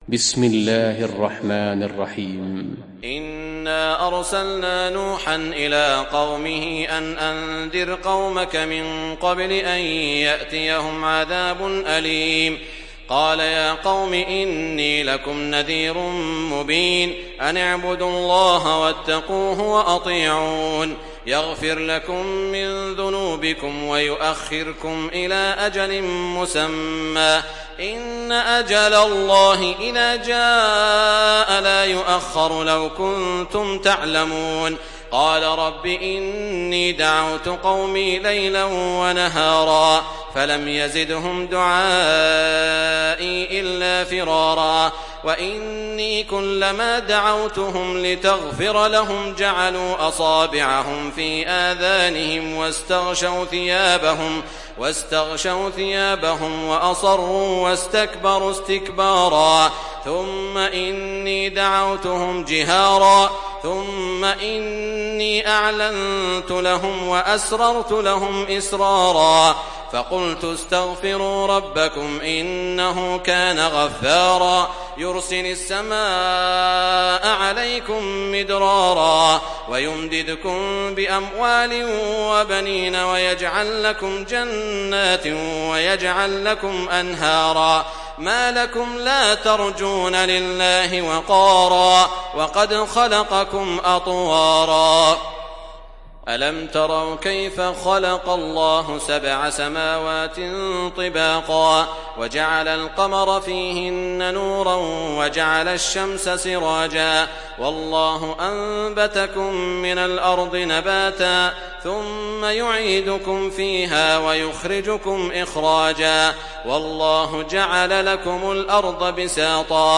Sourate Nuh Télécharger mp3 Saud Al Shuraim Riwayat Hafs an Assim, Téléchargez le Coran et écoutez les liens directs complets mp3